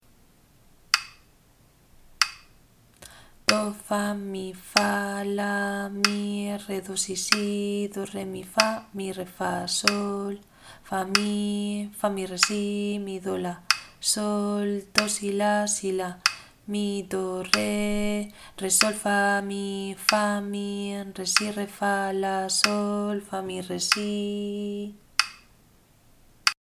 ritmo_3_7_repaso.mp3